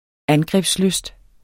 Udtale [ ˈangʁεbs- ]